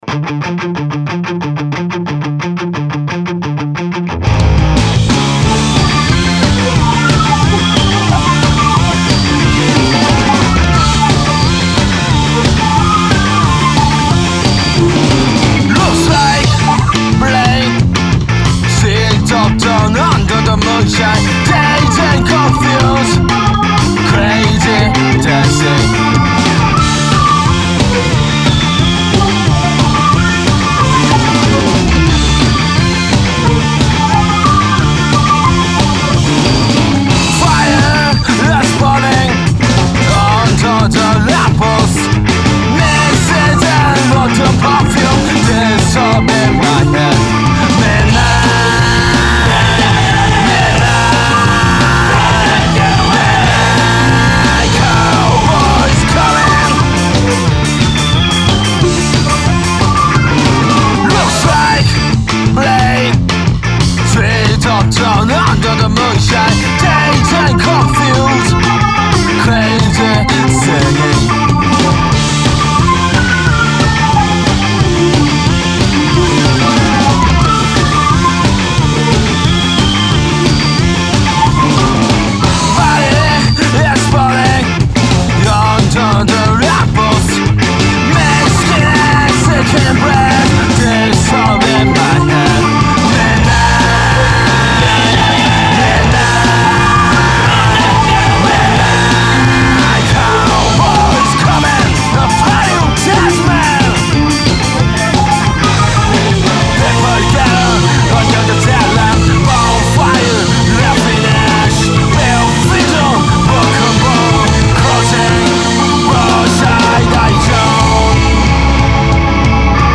ska band
Vocals
Bass
Drums
Organ
Guitar